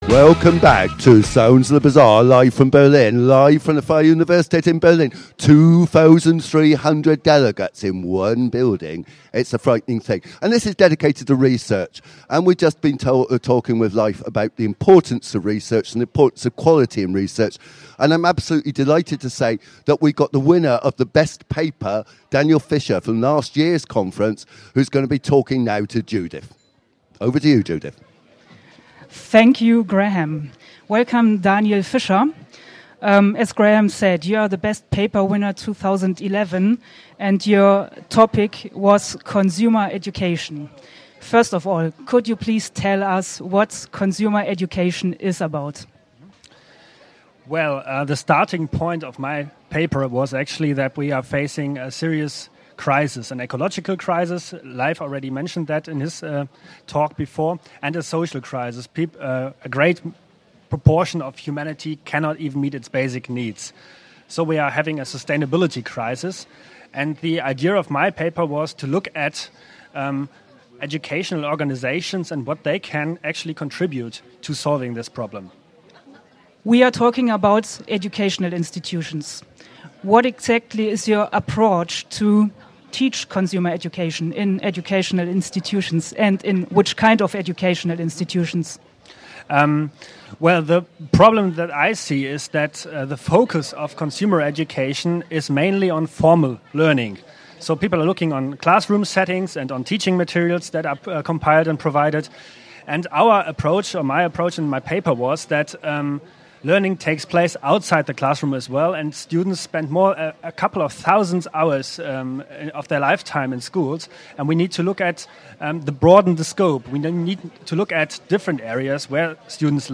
Medienbezeichnung/OutletRadio-Interview